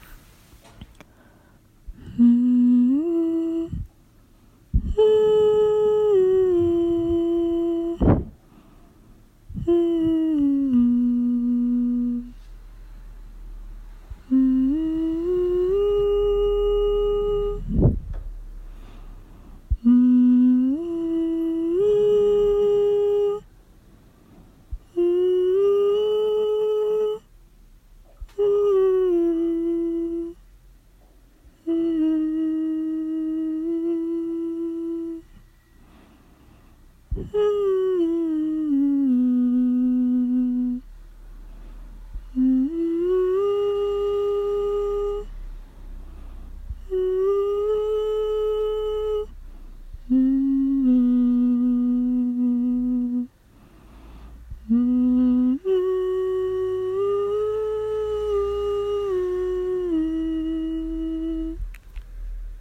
humming1.mp3